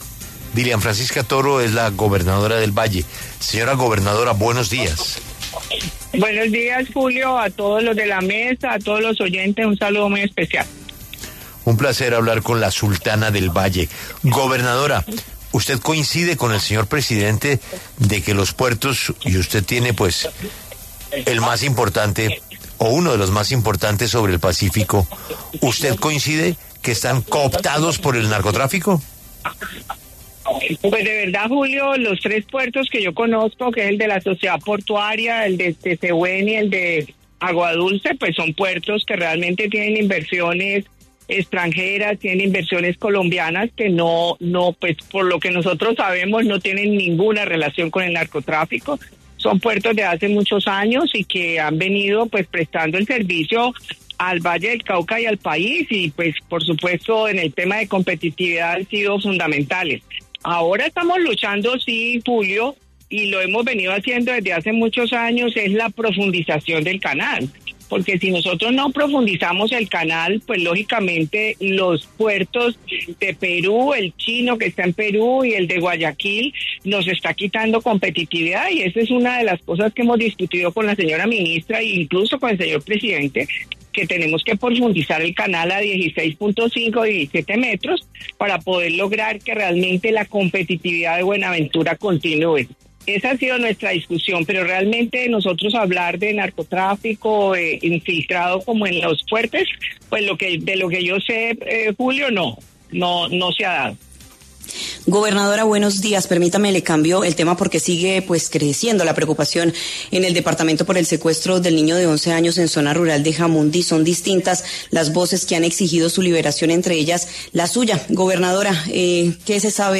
En entrevista con La W, aseguró que el caso ha causado profunda indignación en todo el departamento y pidió a los captores su liberación inmediata.